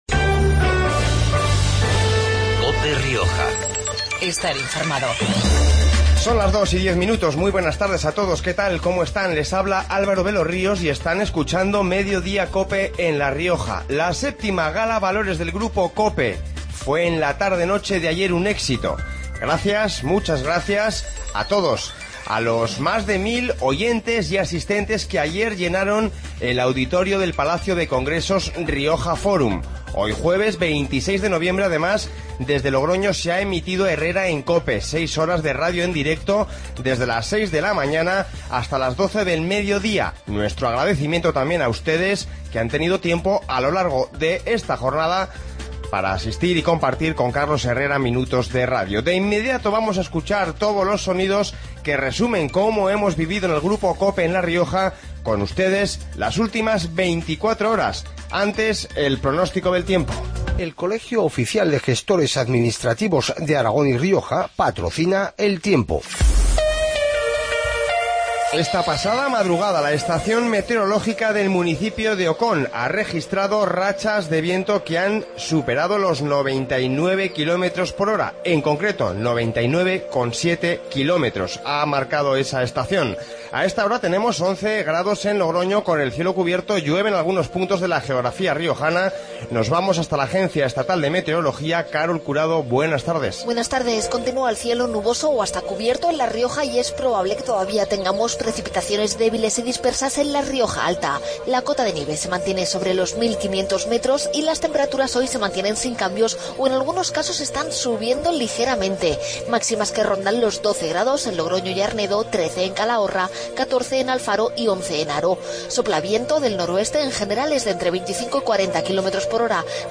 Informativo Mediodia en La Rioja 26-11-15